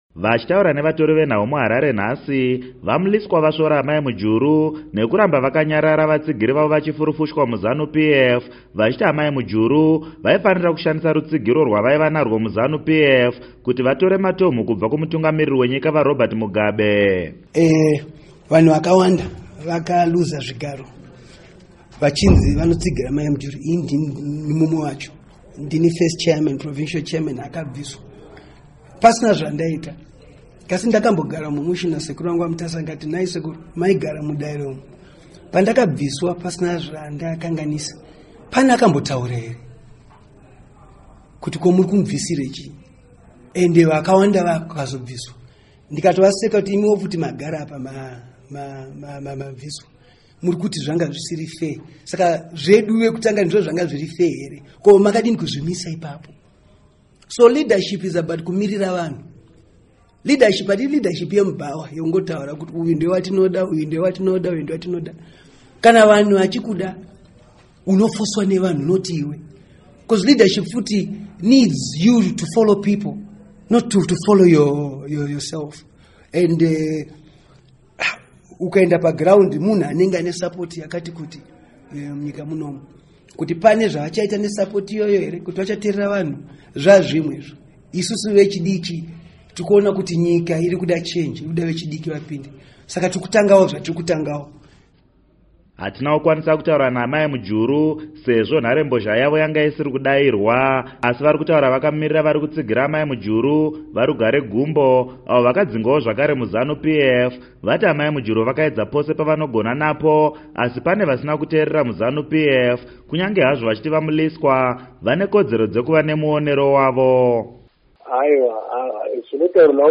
Vachitaura nevatori venhau mu Harare nhasi, Va Mliswa vasvora Amai Mujuru nekuramba vakanyarara vatsigiri vavo vachifurufushwa mu Zanu PF vachiti Amai Mujuru vaifanira kushandisa rutsigiro rwavaiva narwo mu Zanu PF kuti vatore matomhu kubva kumutungamiri wenyika, Va Robert Mugabe.